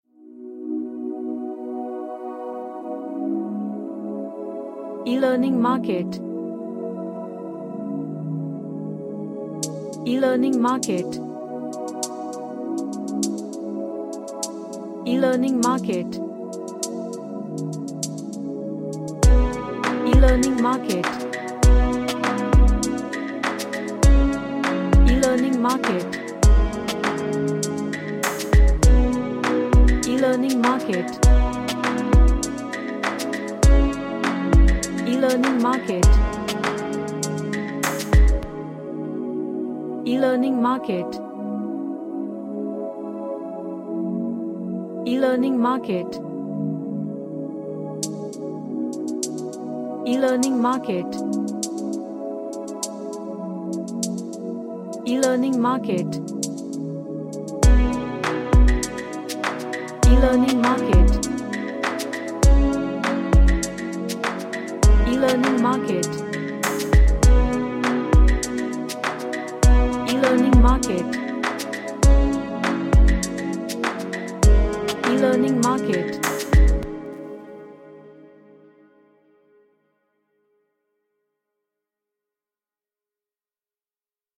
An Ambient track with lots of pads.
Sad / Nostalgic